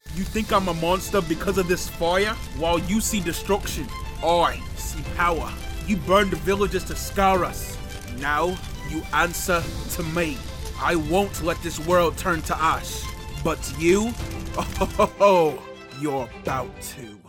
Teen
Young Adult
Character Voice